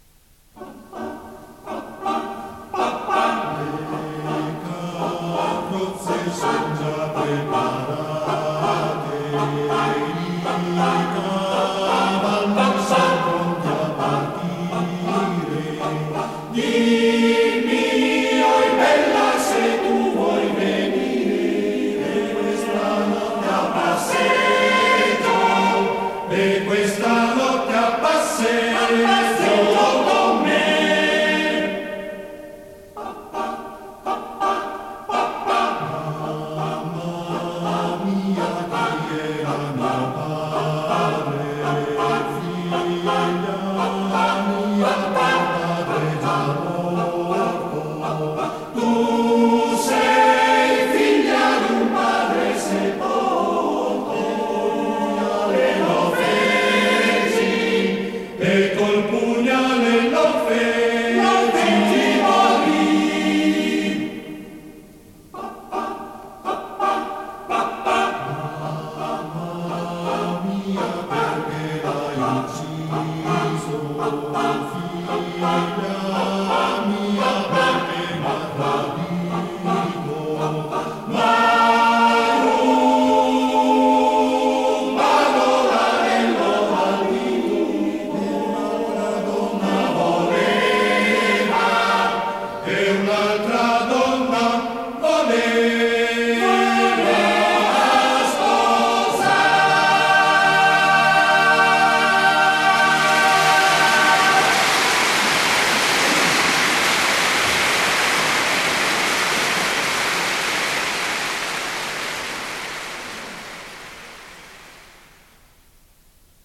Arrangiatore: Dionisi, Renato (Armonizzatore)
Esecutore: Coro CAI Uget